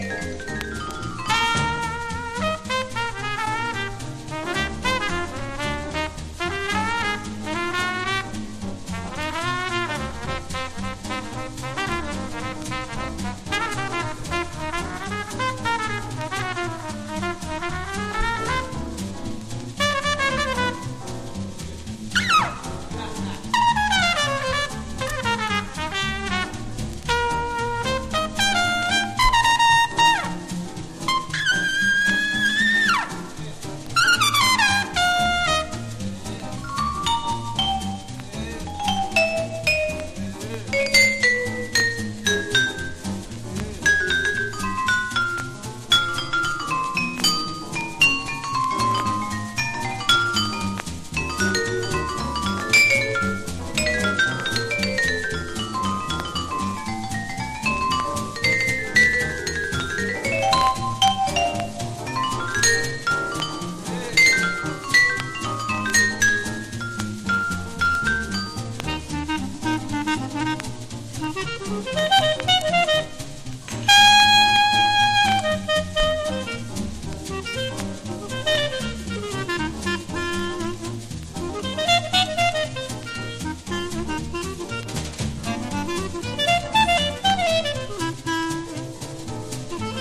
水面を飛び跳ねる水玉のようなビブラフォンの響きが心地よすぎます。
(全体的にチリノイズ入ります)